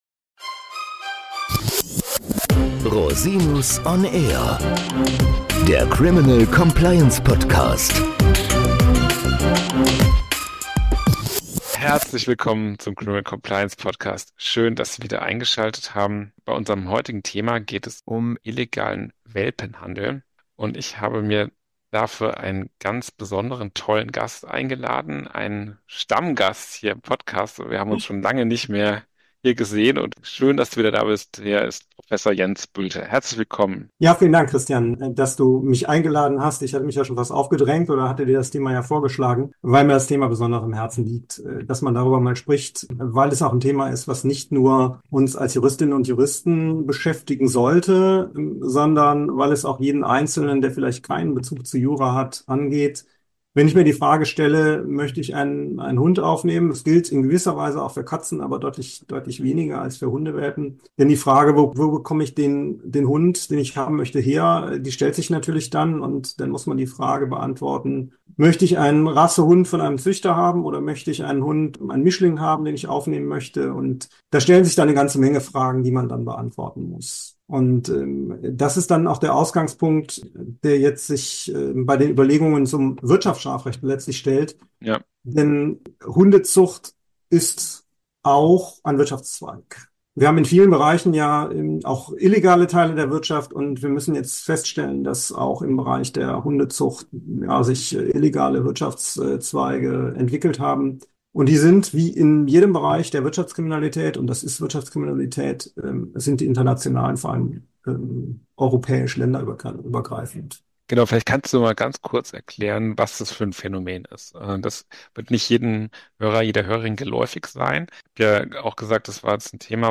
Illegaler Welpenhandel – Interview